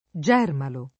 [ J$ rmalo ]